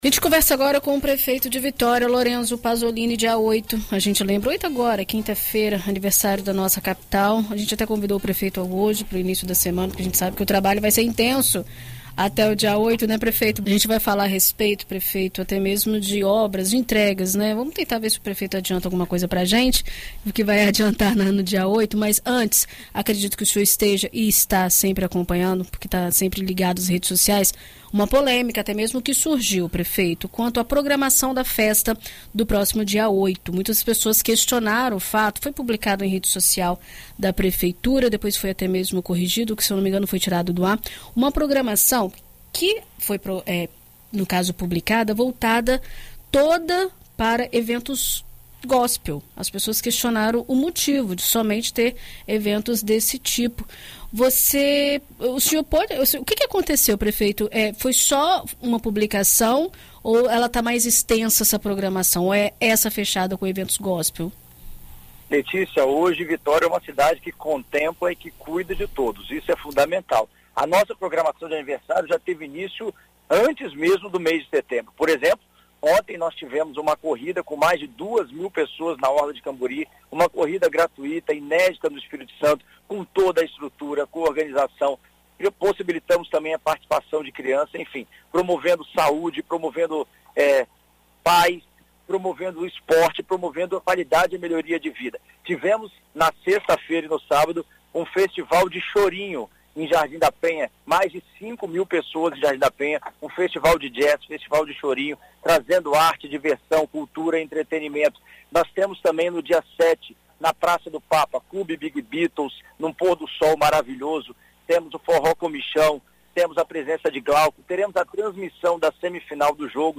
Em entrevista à BandNews FM Espírito Santo nesta segunda-feira (05), o prefeito do município, Lorenzo Pazolini, fala sobre as ações que serão realizadas nesta semana, com eventos festivos no Praça do Papa, além do anúncio de uma nova iluminação da Cinco Pontes e a construção do Trevo de Goiabeiras, que irá melhorar o fluxo na região.